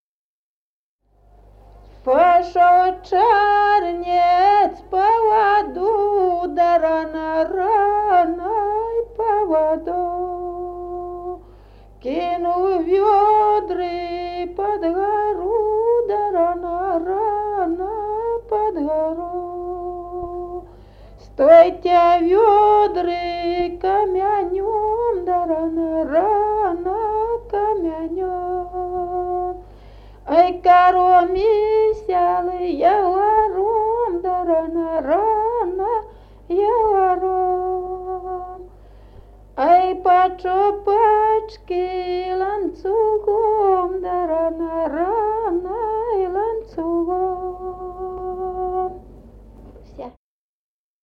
Народные песни Стародубского района «Пошёл чернец по воду», гряные.
с. Мохоновка.